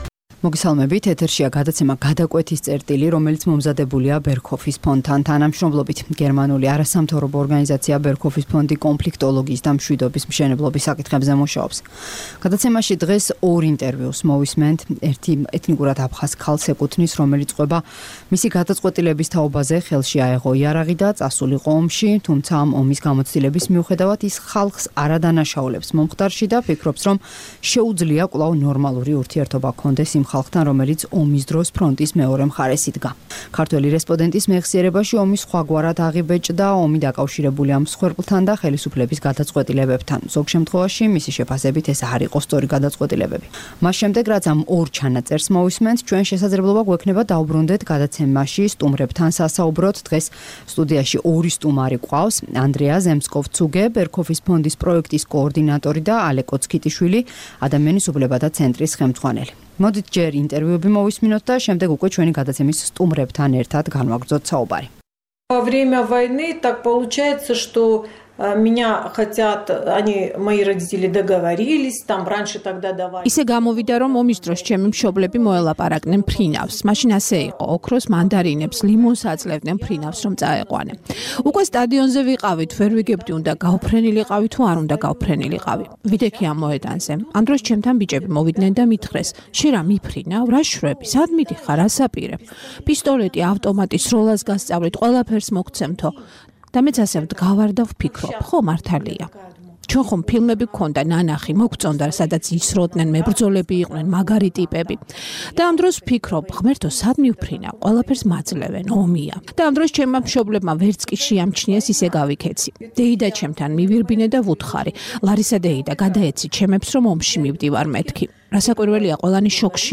გადაცემაში შემოგთავაზებთ ორ ინტერვიუს. ეთნიკურად აფხაზი ქალი ჰყვება ომში წასვლის თაობაზე მიღებული გადაწყეტილების შესახებ - იმაზე, თუ როგორ აიღო ხელში იარაღი ჯერ თვითონ და შემდეგ უკვე მისმა ძმამ, როგორ იბრძოდა, თუმცა, ამის მიუხედავად, ის ხალხს არ ადანაშაულებს მომხდარში და ფიქრობს, რომ შეუძლია კვლავ ნორმალური ურთიერთობა ჰქონდეს იმათთან, ვინც ომის დროს ფრონტის მეორე მხარეს იდგა.